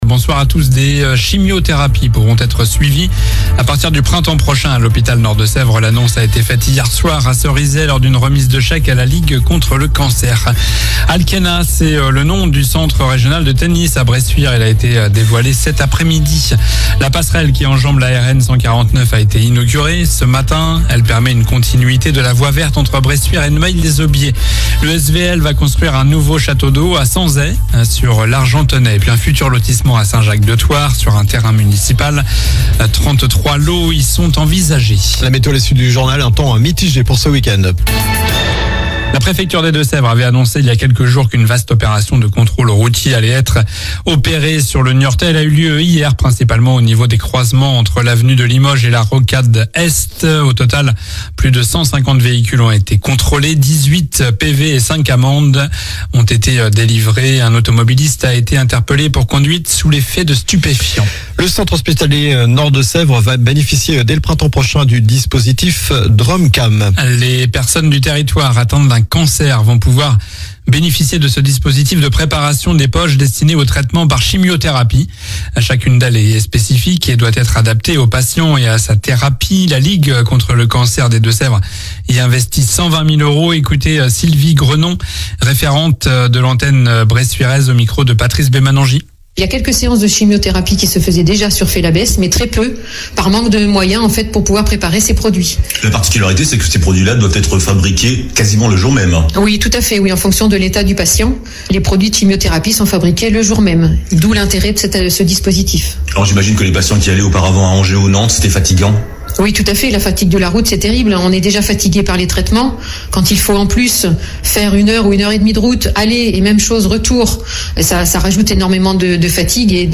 Journal du vendredi 20 décembre (soir)